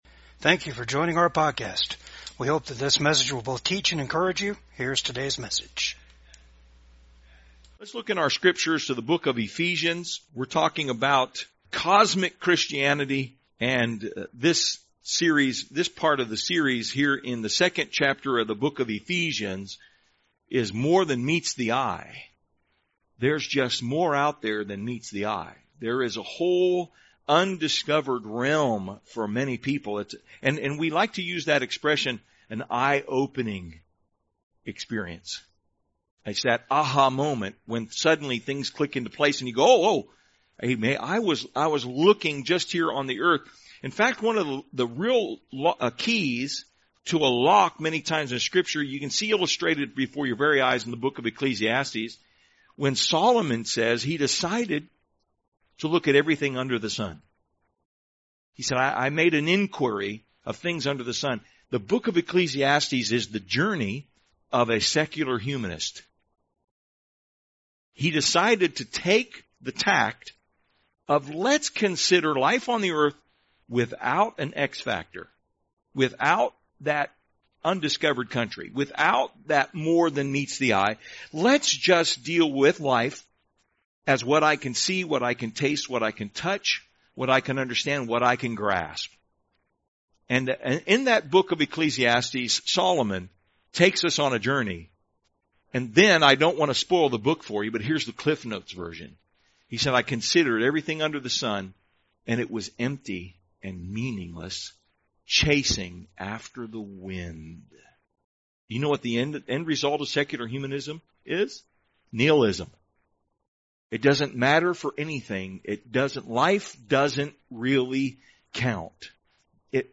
Ephesians 2:1-9 Service Type: VCAG SUNDAY SERVICE GOD NEVER MADE HELL FOR MANKIND!